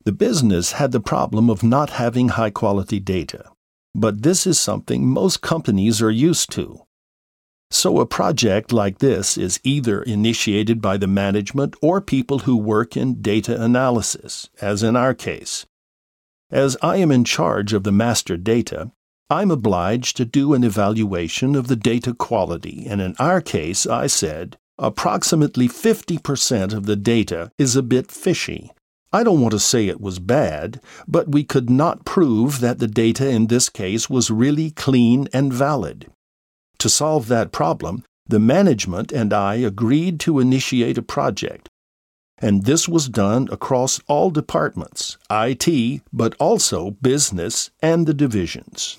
American Speaker, off speaker, advertizing, Internet, Industry, TV....Voice color: Middle to deep.
Sprechprobe: Industrie (Muttersprache):